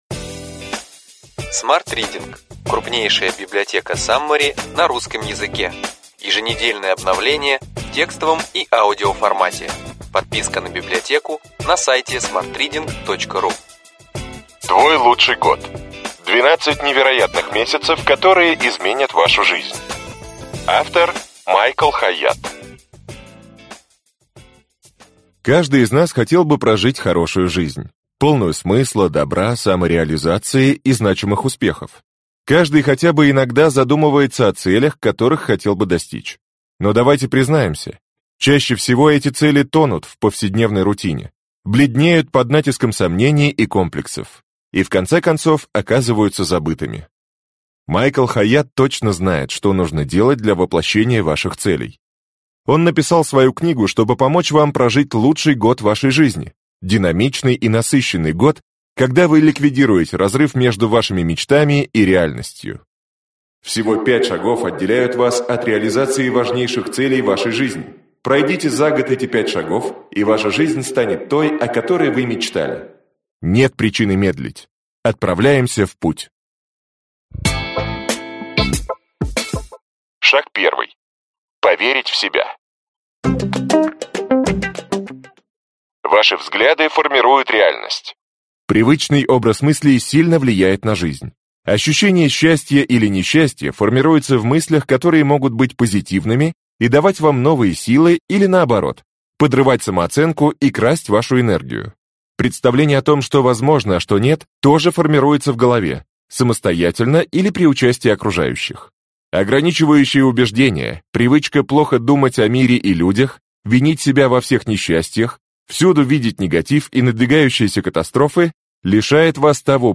Аудиокнига Цель! Как определять и достигать. 12 самых важных книг о том, как превращать мечты в реальность | Библиотека аудиокниг